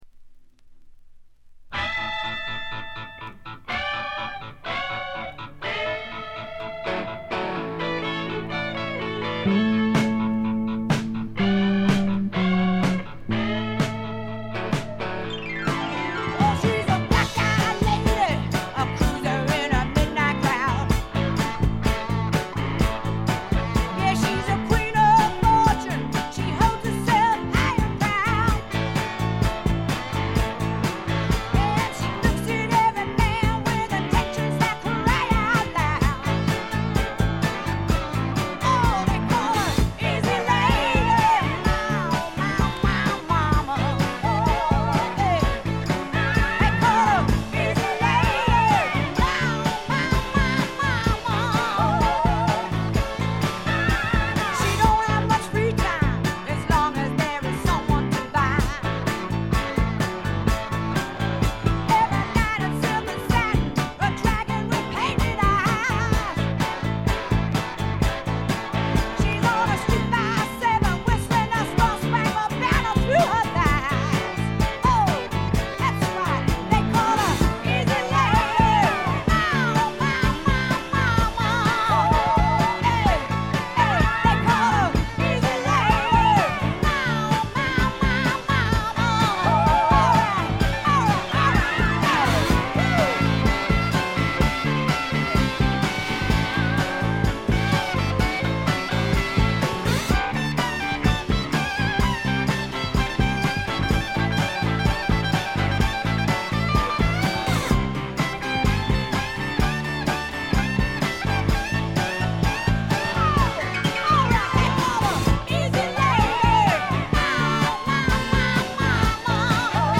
部分試聴ですが、わずかなノイズ感のみ。
ファンキーでタイト、全編でごきげんな演奏を繰り広げます。
試聴曲は現品からの取り込み音源です。
Recorded At - The Sound Factory